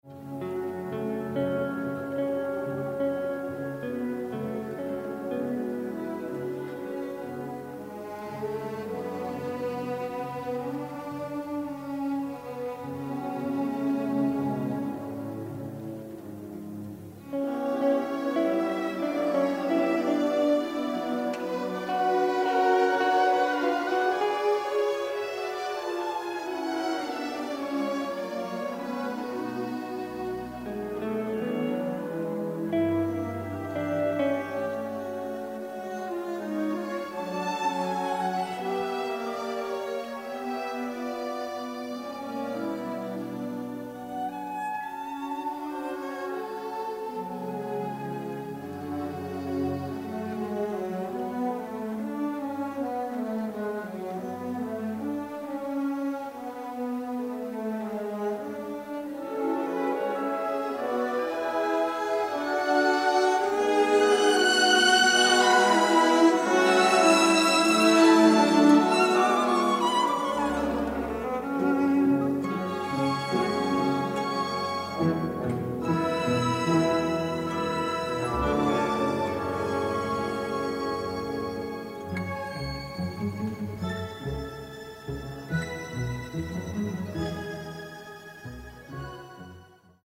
robust orchestral score